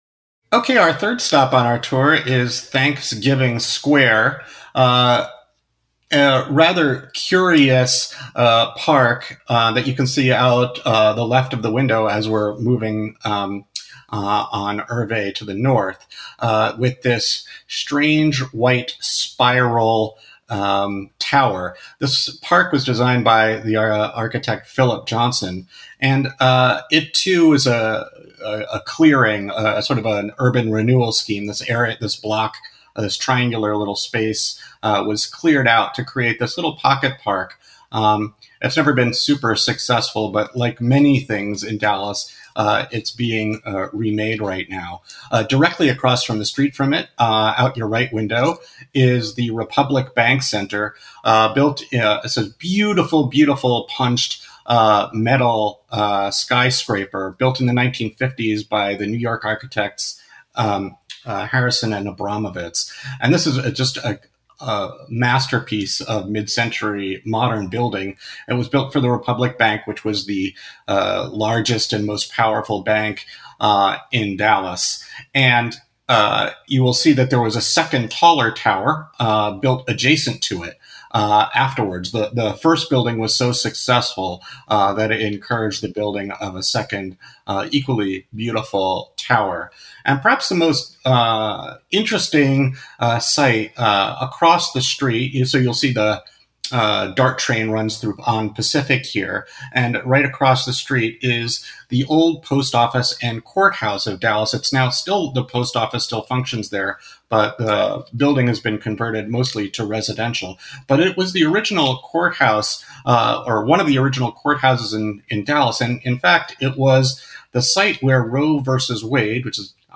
The men point out that across the street on Pacific is the old post office and courthouse of Dallas (1:39) .